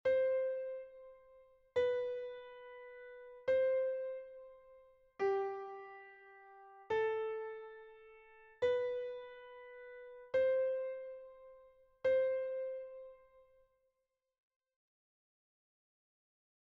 Exercise 4: C, B + G, A.
4_C_C_B_G_A.mp3